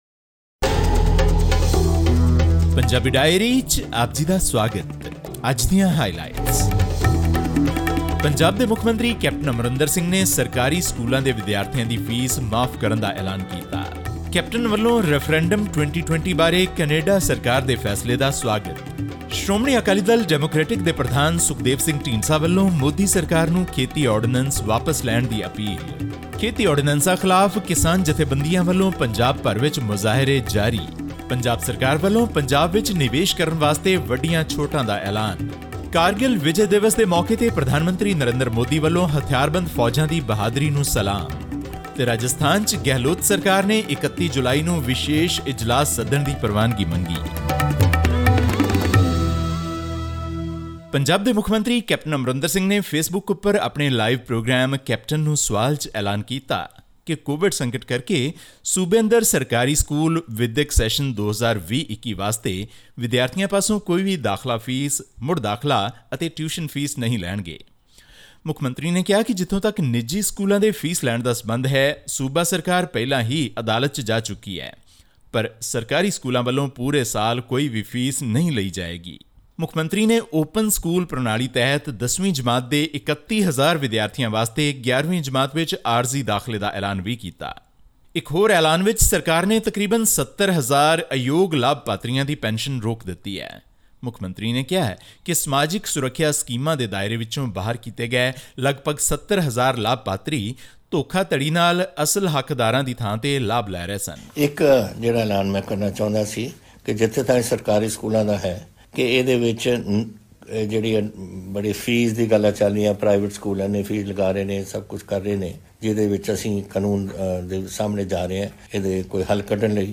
Tune into this week's news wrap from Punjab, to know about the waiver given to all government schools by Chief Minister Capt Amarinder Singh for a year in the wake of COVID-19; the state government's welcome of Canada's official rejection of Referendum 2020 and the continued protests against the Narendra Modi-government's agriculture ordinance in the state and New Delhi.